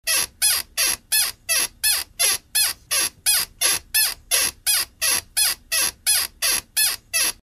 Звуки скрипа кровати
Звук скрипящей кровати